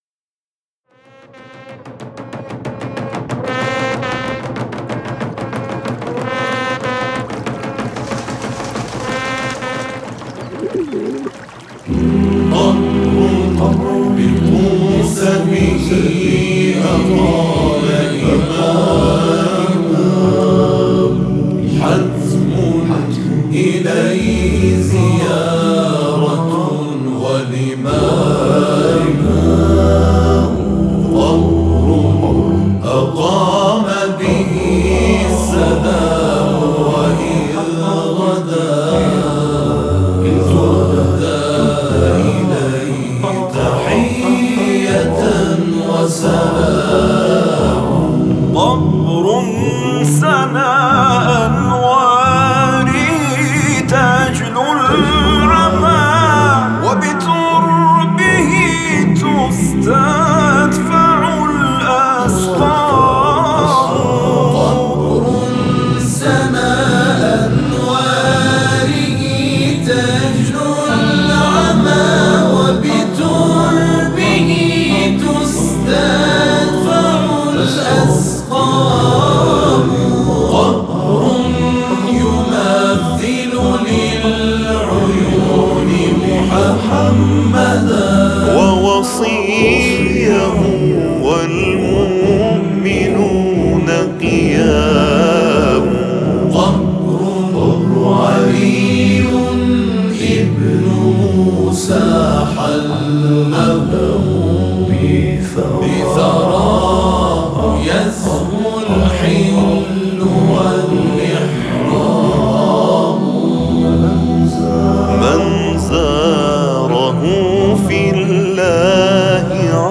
به صورت آکاپلا تولید شده است